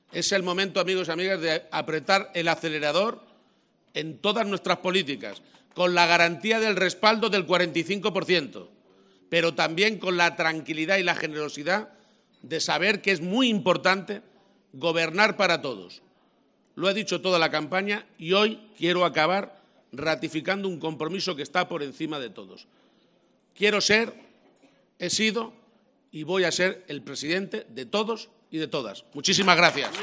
En el toledano Cigarral del Ángel Custodio, donde ha sido recibido al grito de “presidente”, Emiliano García-Page ha agradecido “de corazón” la contribución de “todos los que me habéis apoyado, me habéis ayudado y, por supuesto, a todos los que me habéis votado”, ha señalado.